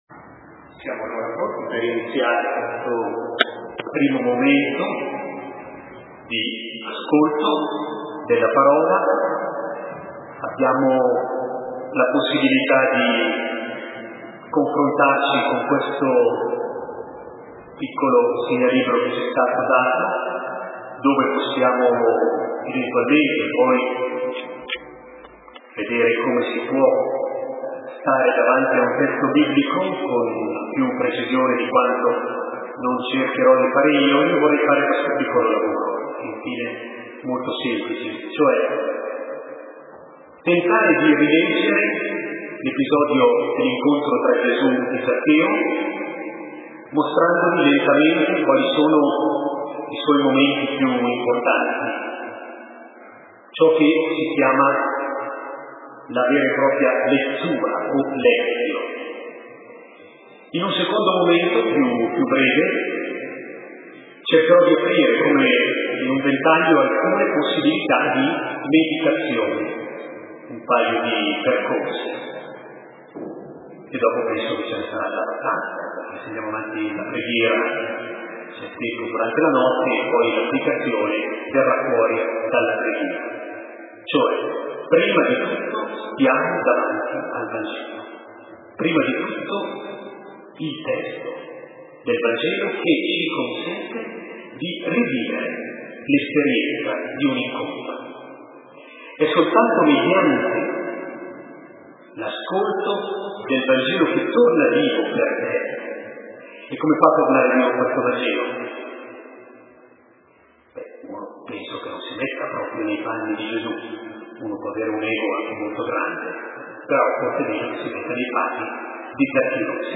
15 Ottobre SE NON COSI’ COME?. . .circa 80 giovani insieme con noi per ripartire con le proposte del nuovo anno a cui fa da sfondo il tema: IN LUI, VITA NUOVA.